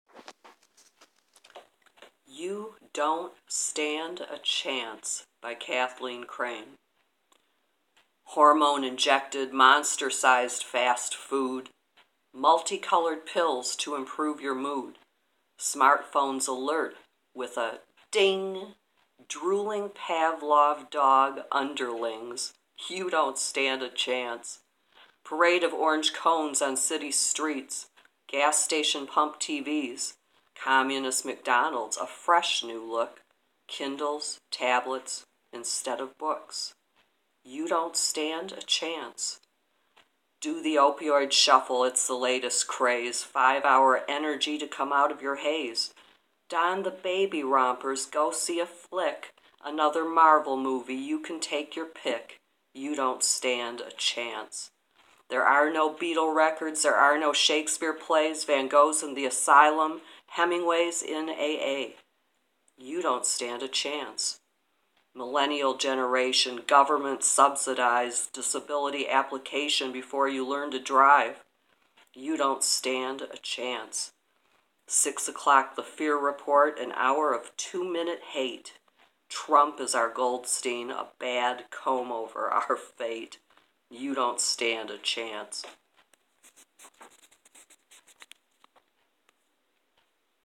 Poem: